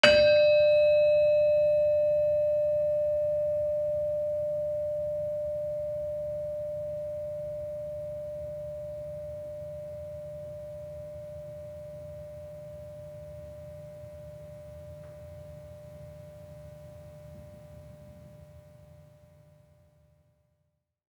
Gamelan Sound Bank
Gender-4-D4-f.wav